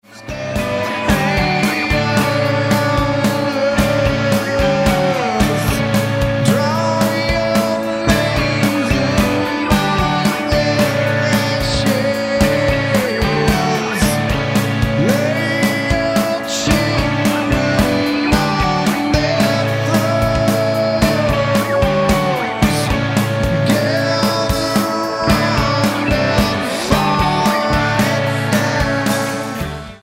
guitar
drums
bass